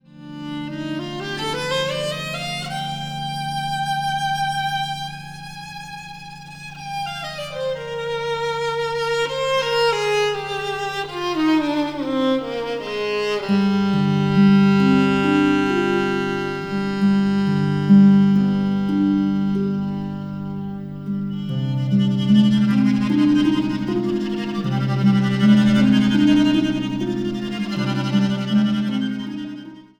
Instrumentaal | Harp
Instrumentaal | Viool